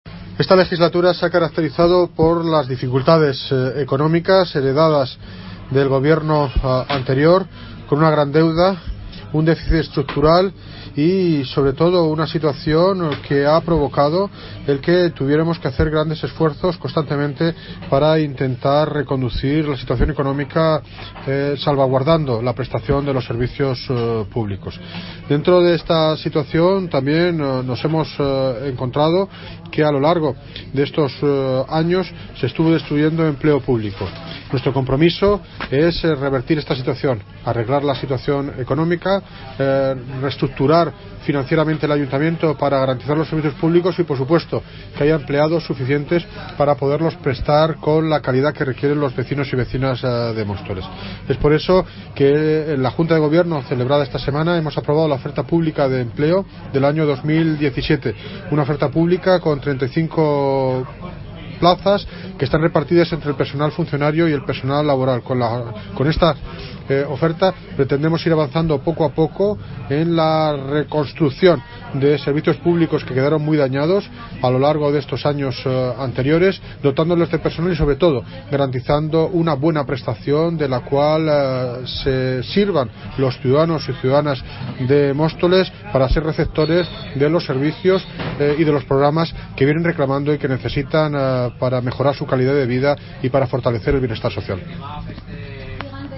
Audio - David Lucas (Alcalde de Móstoles) Sobre Oferta Empleo Público